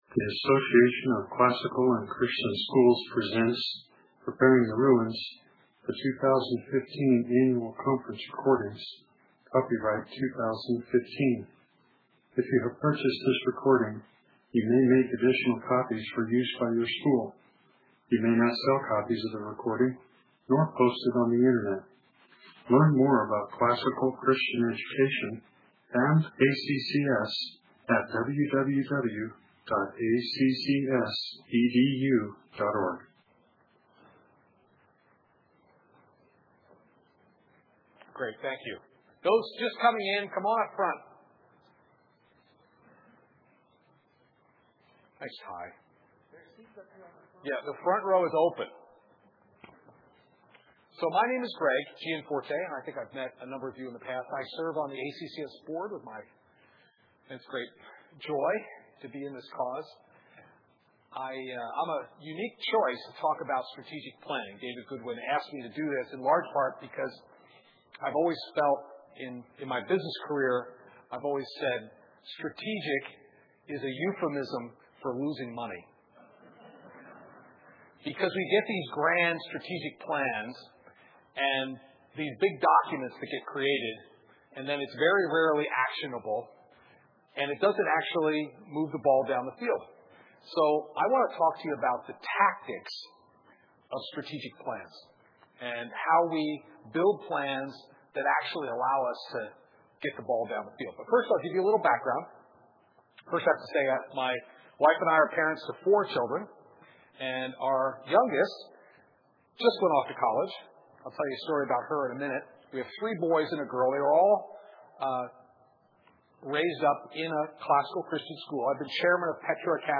2015 Leaders Day Talk | 0:48:51 | Leadership & Strategic, Marketing & Growth
Expect an interactive session.